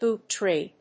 アクセントbóot trèe